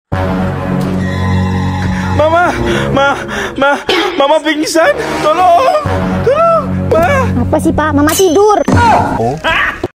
kucing lucu bikin ngakak sound effects free download